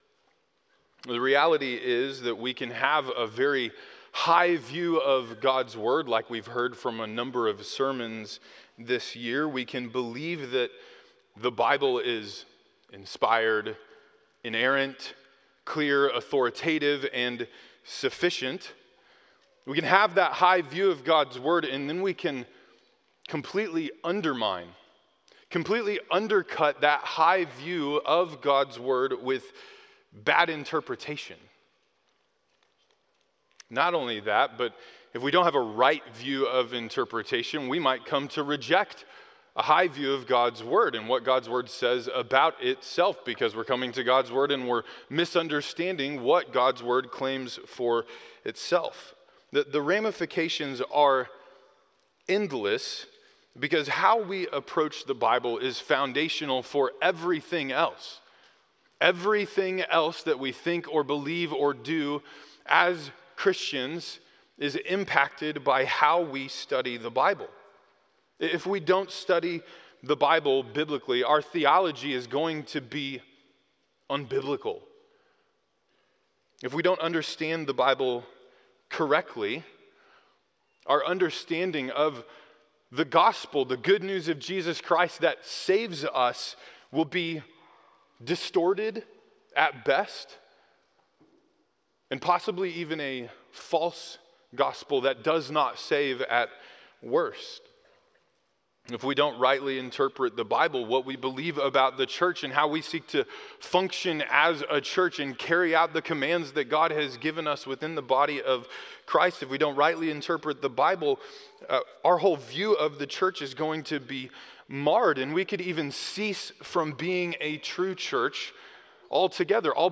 Sermons
sunday-morning-5-25-25.mp3